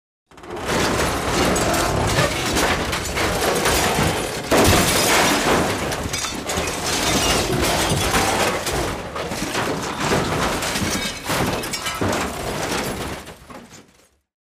На этой странице собраны реалистичные звуки разрушения зданий: обвалы, взрывы, треск конструкций.
Разрушение здания экскаватором